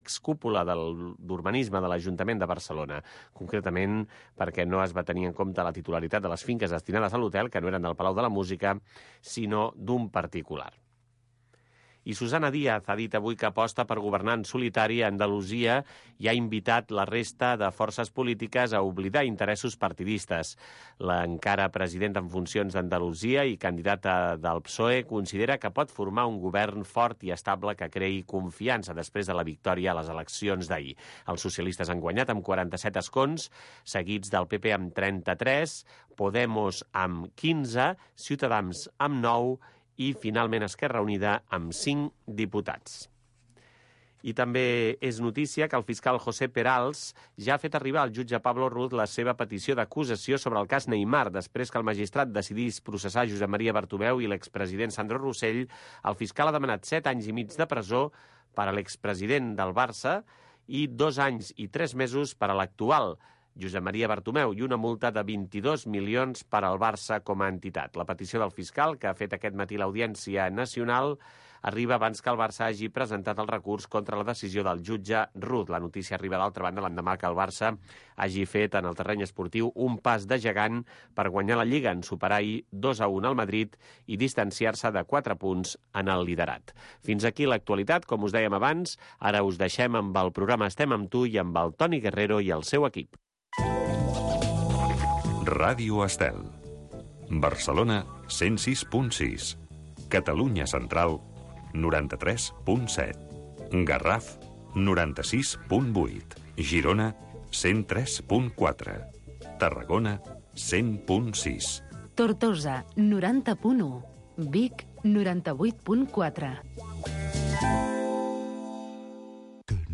Estem amb tu. Magazín cultural de tarda.